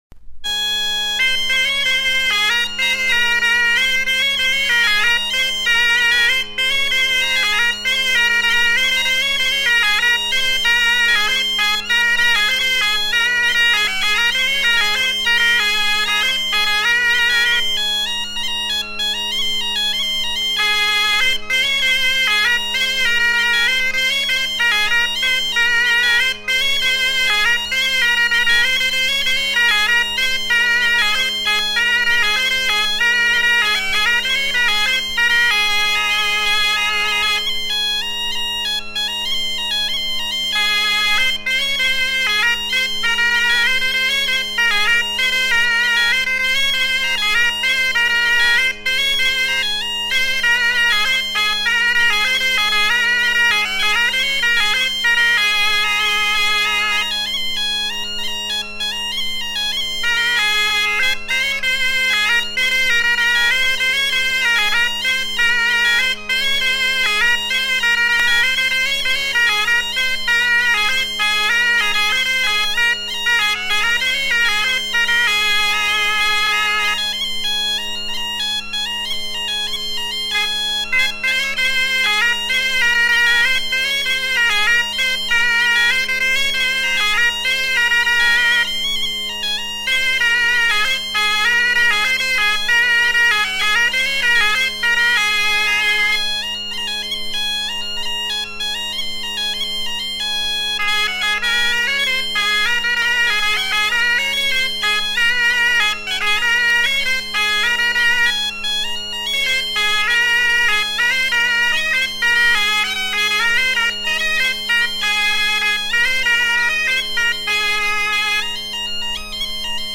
Deux airs de chansons à danser l'en dro recueillis vers 1980 à Sarzeau
danse : an dro